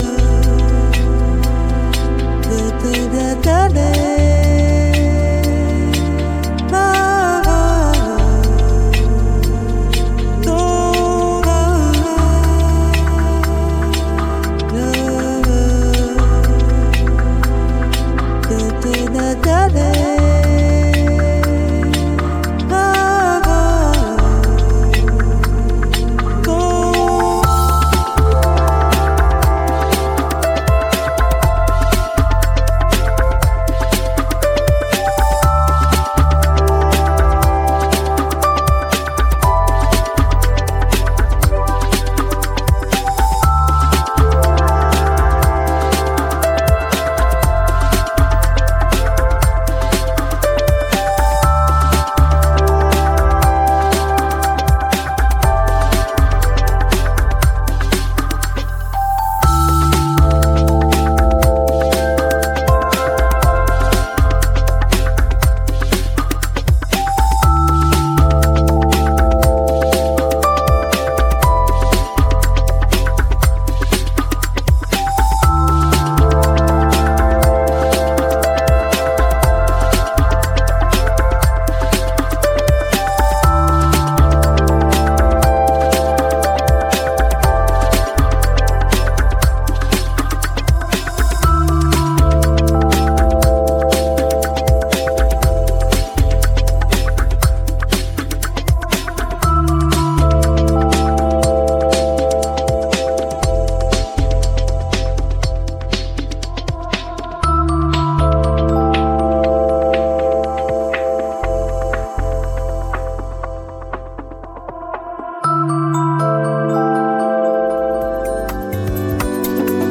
Инструментальная музыка